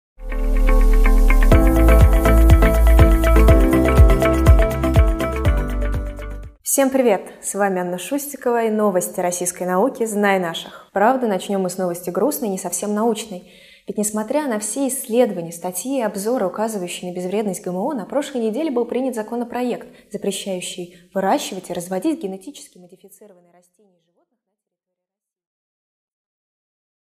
Аудиокнига О метагеномах мутациях и выборе предметов в школе | Библиотека аудиокниг